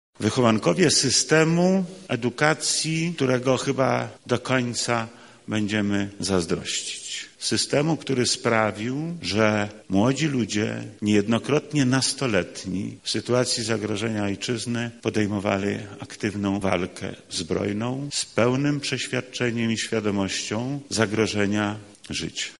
Tak o wyróżnionych mówił w trakcie ceremonii wojewoda lubelski Lech Sprawka.